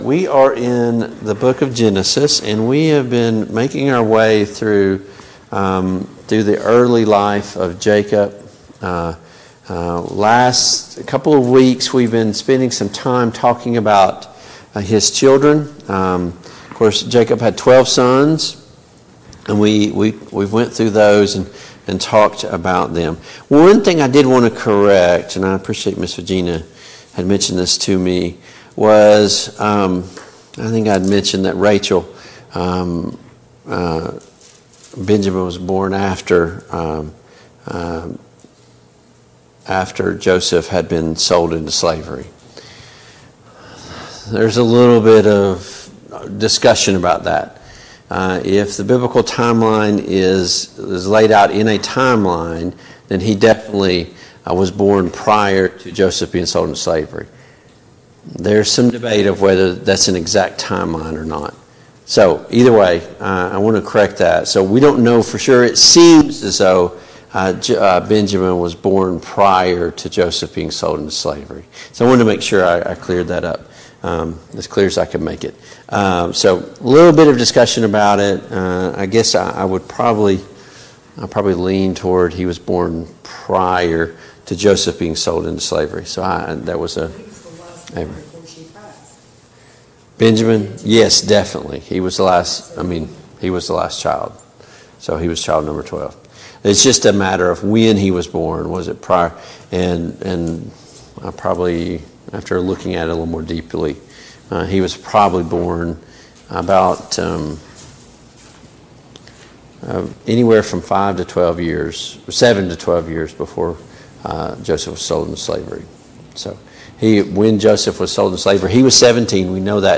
Genesis 31 Service Type: Family Bible Hour Topics: Jacob and Laban « Why do we struggle?